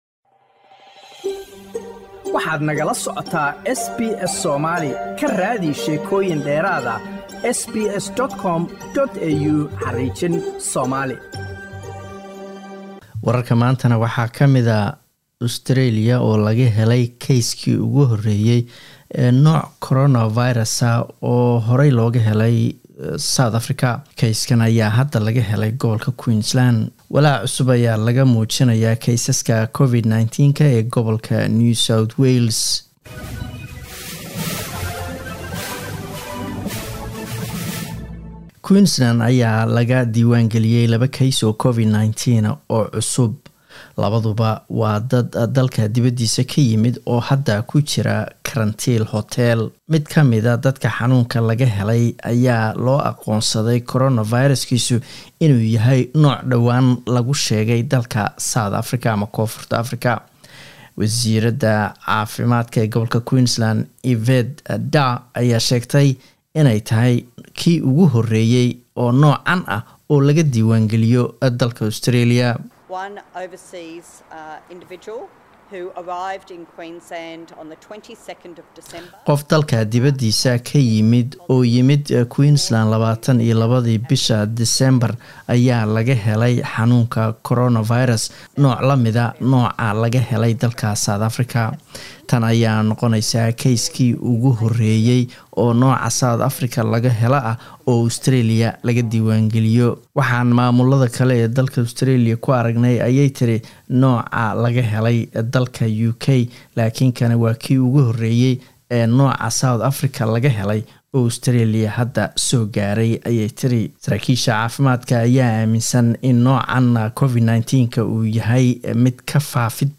Wararka SBS Somali Talaado 29 Disember